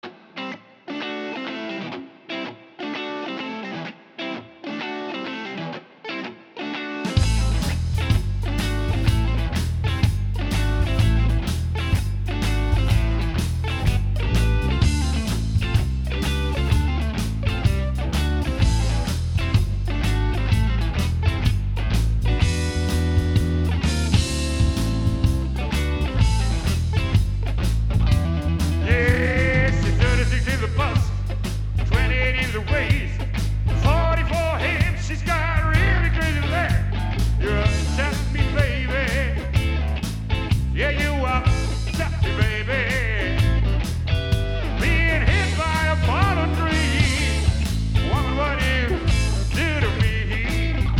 (Proberaumaufnahme)